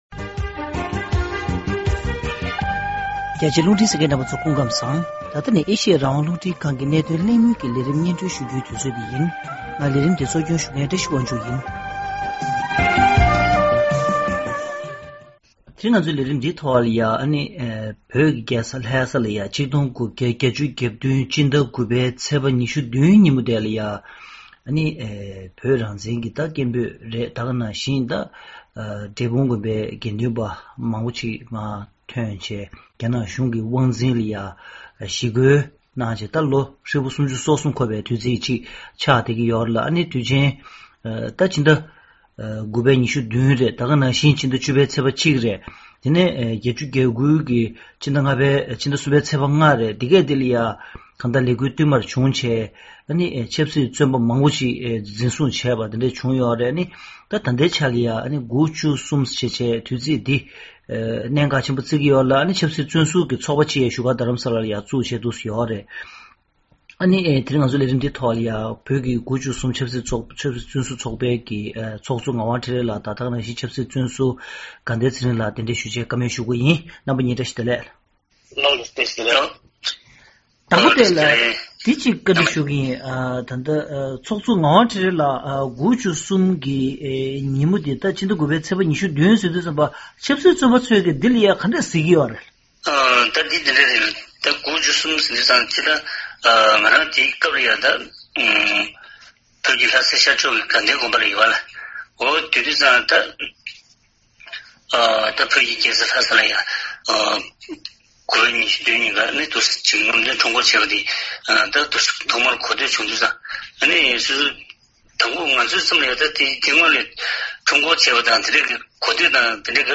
གནད་དོན་གླེང་མོལ་གྱི་ལས་རིམ་ནང་།